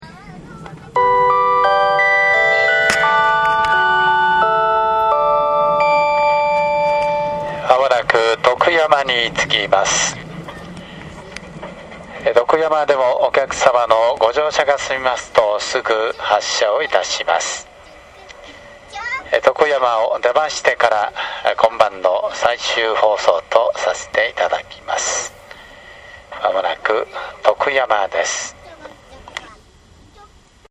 夜も20時半を回り、徳山の手前で
車内放送が入る。（30秒）
0808tokuyamamae.mp3